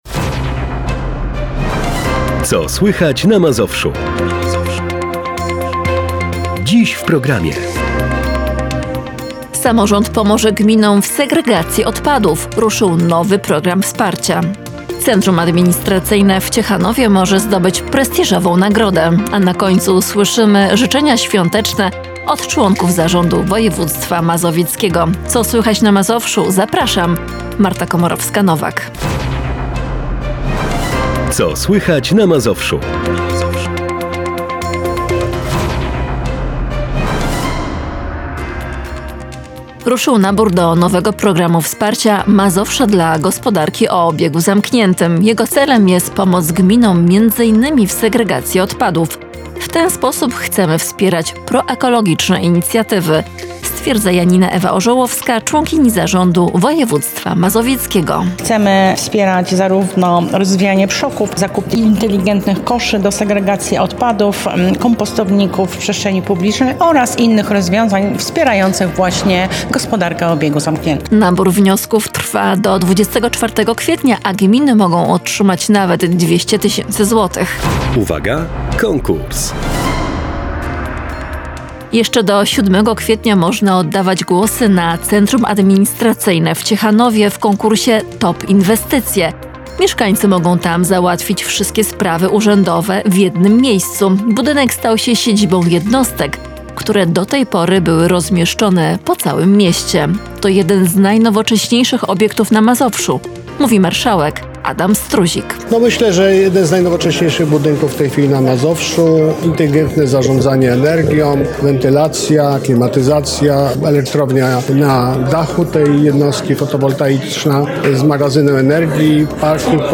STUDIO PLENEROWE NA LODOWISKU - Radio Bogoria
Tym razem nasza ekipa zainaugurowała ferie na lodowisku, zlokalizowanym na mszczonowskich Termach.
Rozmawialiśmy również z instruktorami łyżwiarstwa:) To pierwsze studio plenerowe Radia Bogoria umiejscowione na lodowisku:))